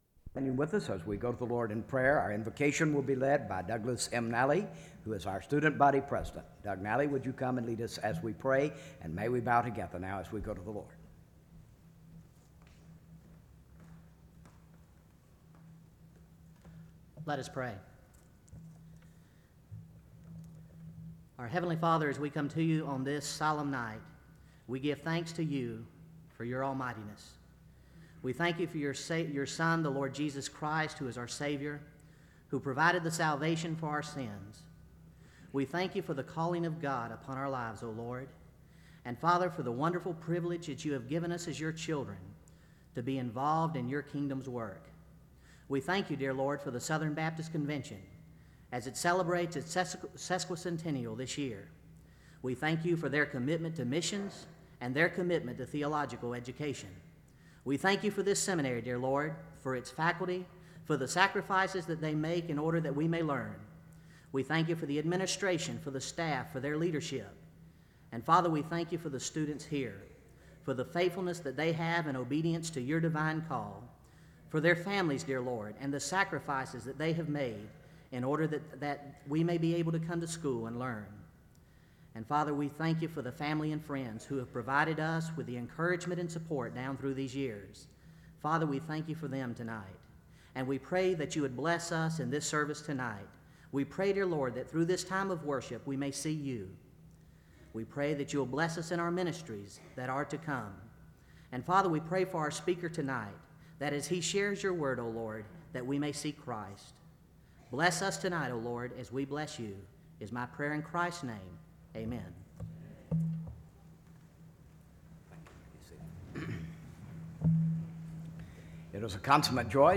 SEBTS Commencement - Richard D. Land May 12, 1995